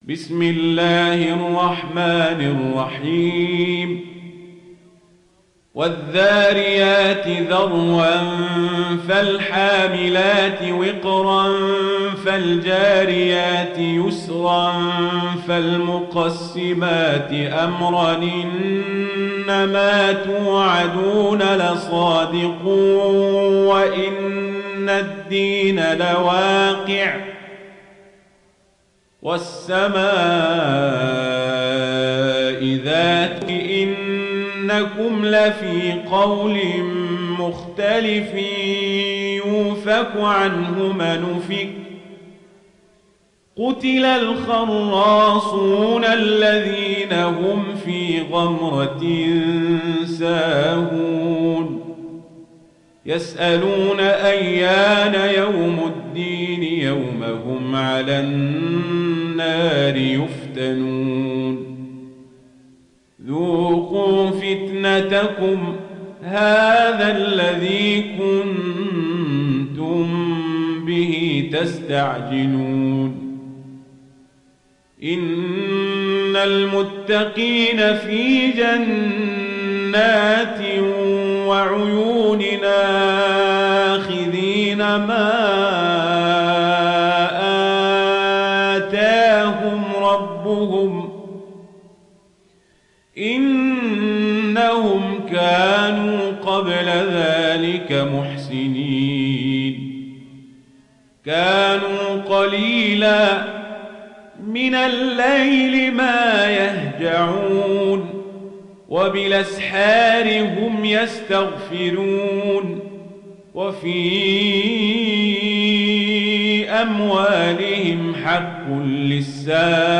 উপন্যাস Warsh থেকে Nafi, ডাউনলোড করুন এবং কুরআন শুনুন mp3 সম্পূর্ণ সরাসরি লিঙ্ক